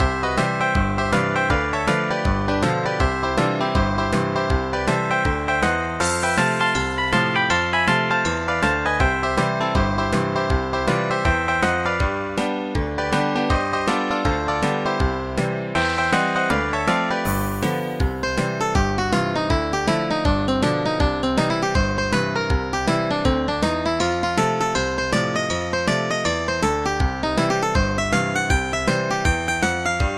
Pulled from game files by uploader
Converted from .mid to .ogg